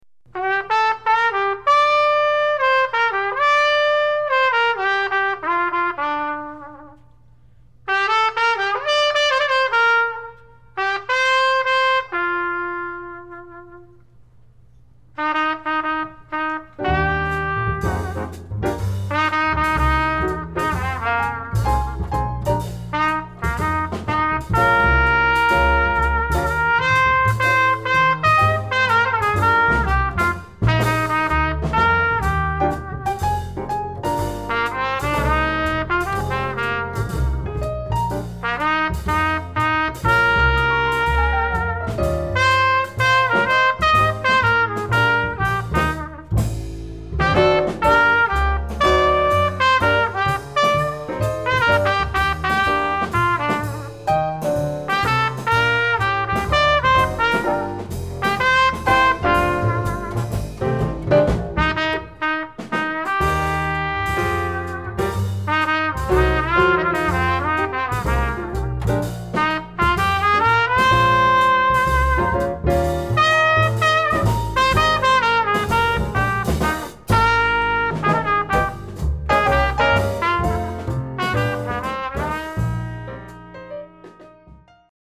standards